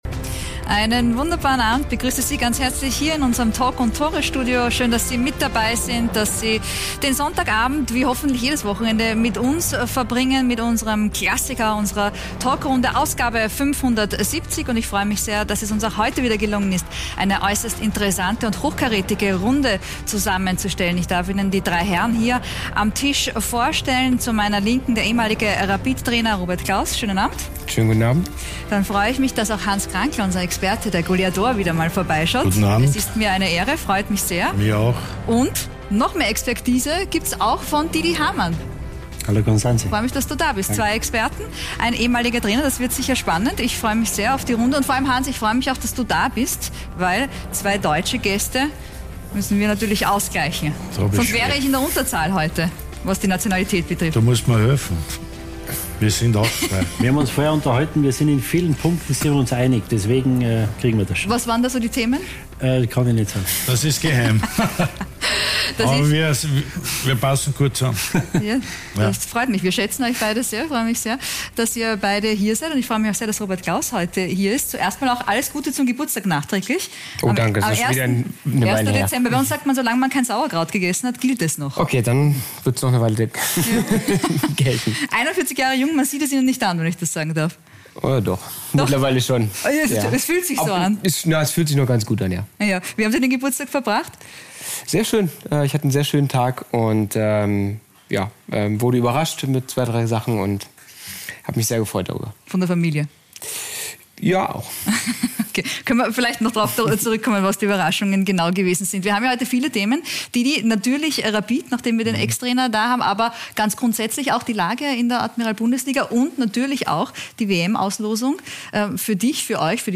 den ehemaligen Rapid-Trainer Robert Klauß sowie die Sky-Experten Didi Hamann und Hans Krankl